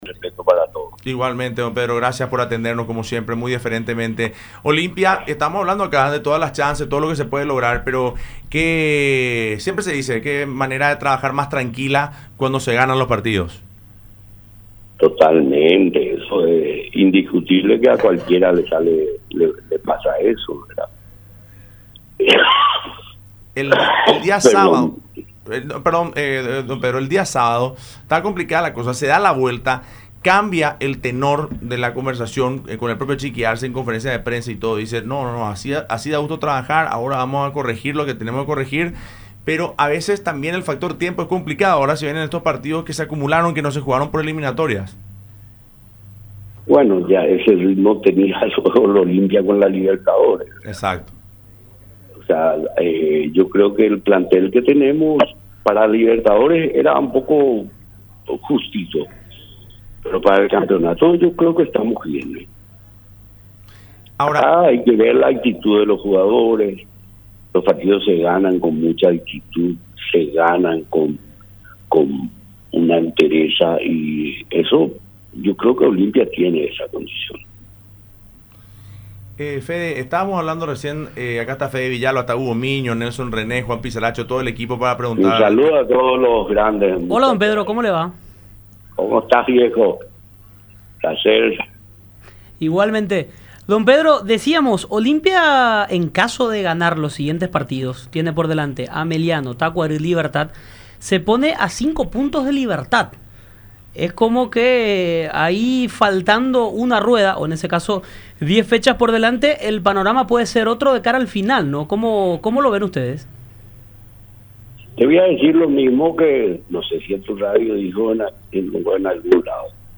En charla con Fútbol Club, a través de radio la Unión y Unión TV, explicó que Garnero a pesar de haber ganado ocho torneos locales, a nivel internacional no le fue bien con Guaraní, Olimpia y Libertad.